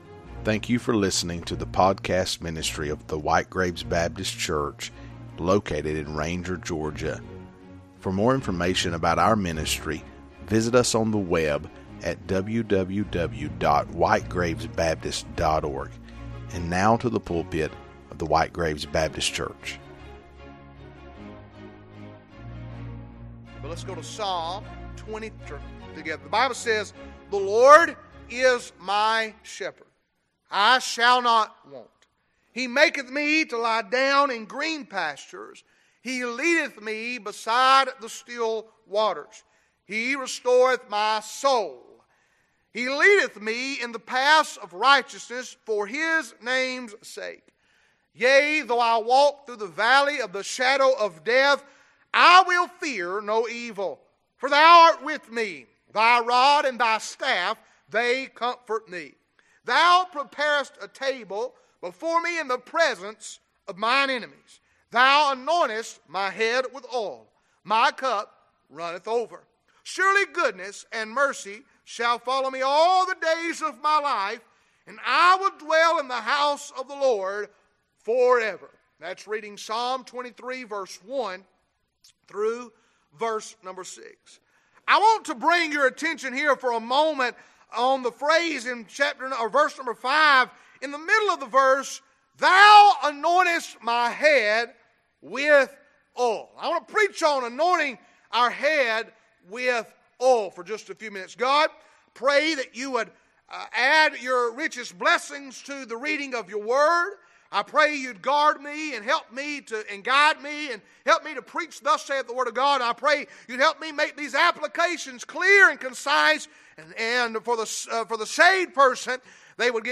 Preached from the pulpit of WGBC on Wednesday Evening 4/1/2020